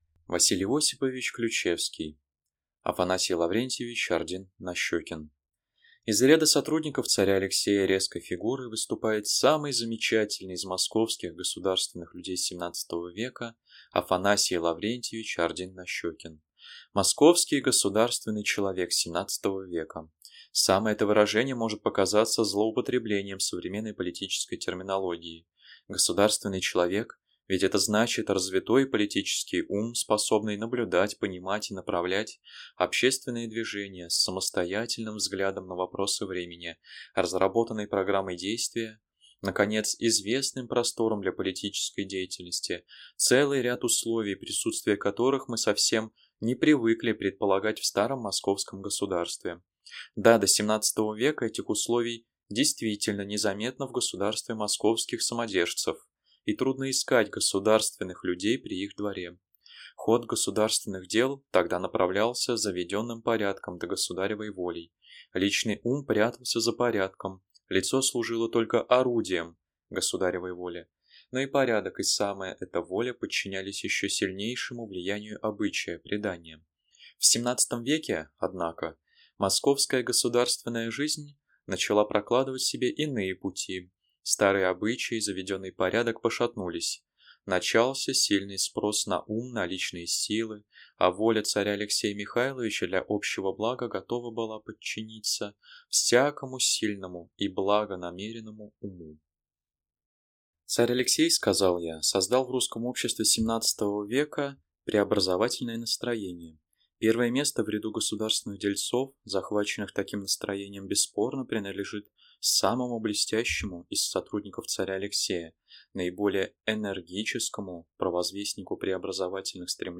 Аудиокнига А. Л. Ордин-Нащокин | Библиотека аудиокниг